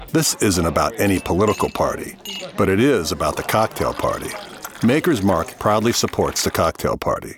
Commercial - Makers Mark
USA English, midwest
Middle Aged
I work from a broadcast-quality home studio.